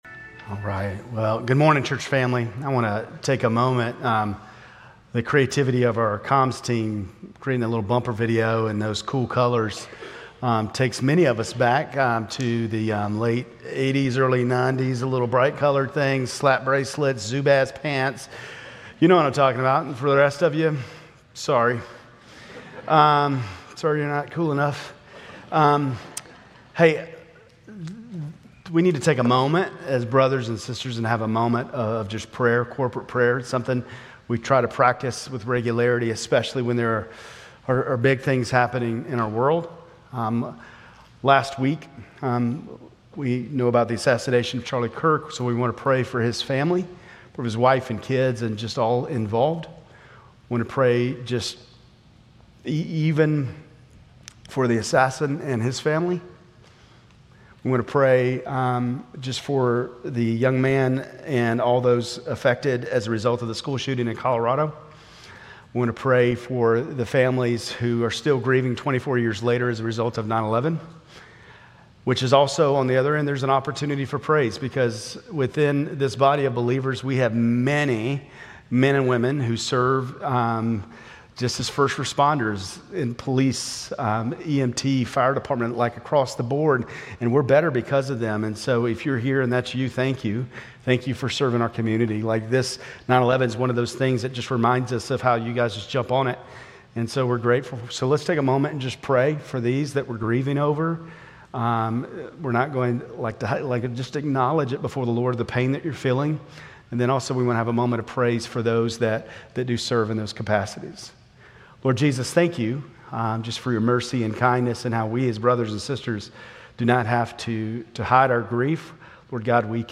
Grace Community Church Lindale Campus Sermons 9_14 Lindale Campus Sep 15 2025 | 00:25:43 1x 00:00 / 00:25:43 Subscribe Share RSS Feed Share Link Embed